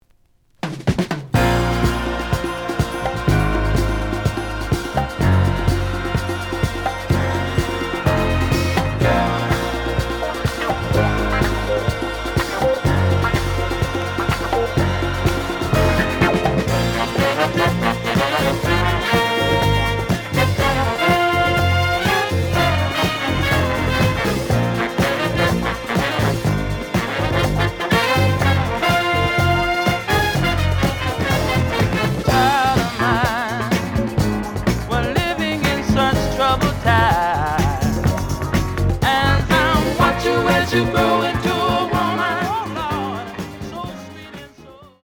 The audio sample is recorded from the actual item.
●Genre: Disco